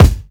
KICK_SWIZ.wav